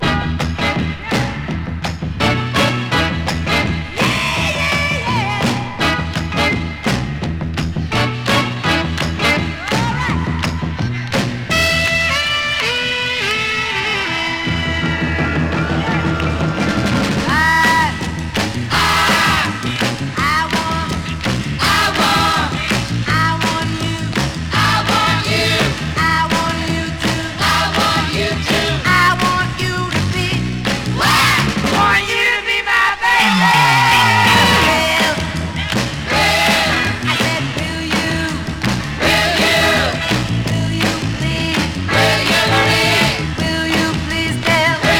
Soul, Funk　UK　12inchレコード　33rpm　Mono